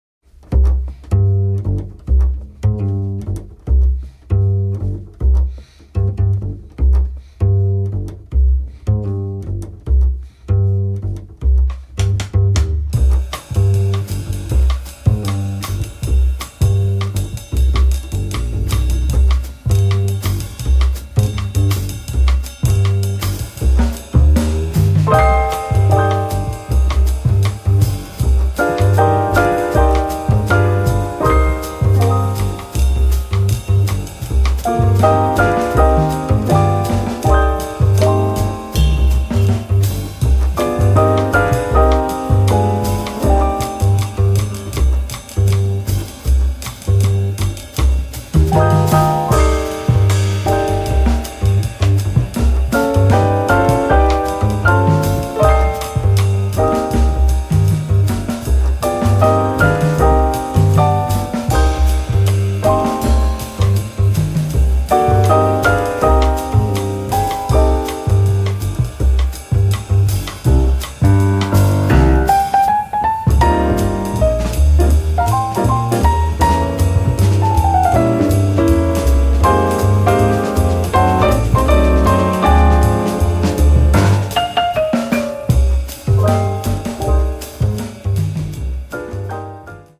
piano
bass
drums